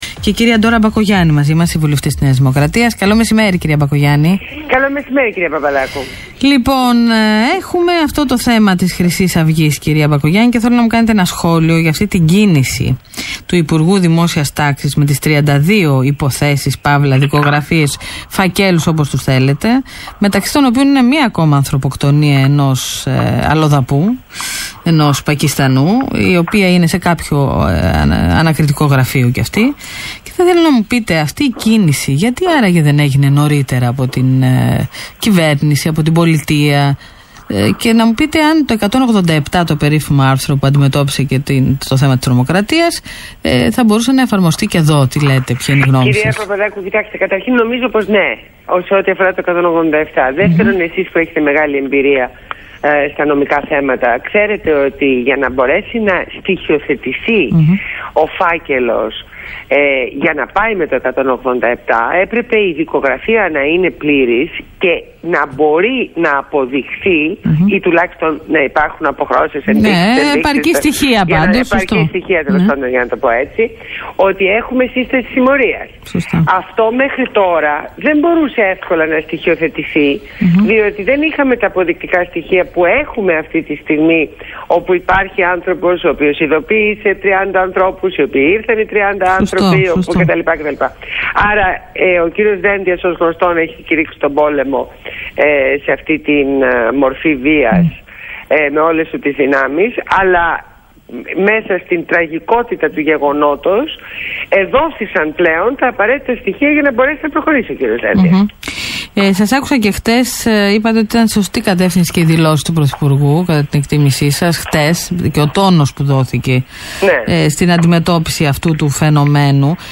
Συνέντευξη στο ραδιόφωνο του ALPHA 989